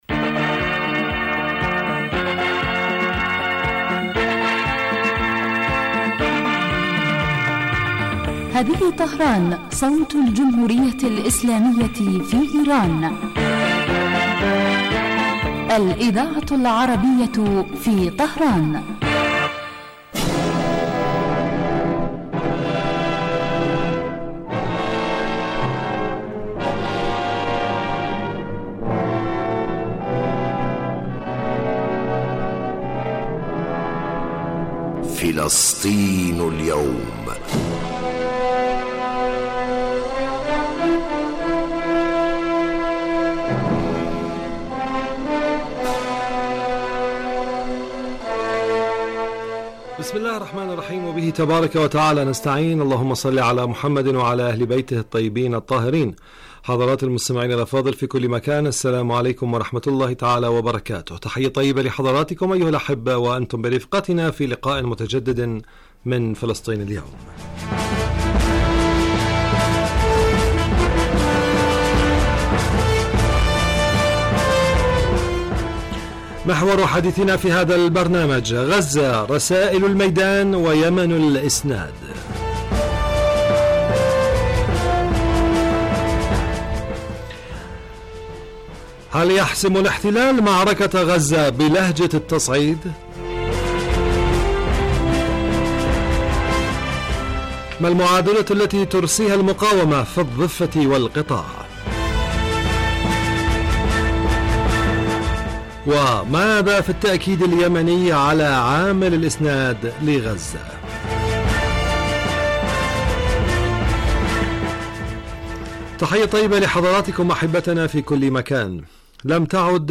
برنامج يتناول تطورات الساحة الفلسطينية على كافة الصعد من خلال تقارير المراسلين واستضافة الخبراء في الشأن الفلسطيني.